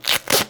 CHOMPStation2/sound/effects/tape.ogg at e4d8e5263e98d6c5655d292355f17be3e986e45d
tape.ogg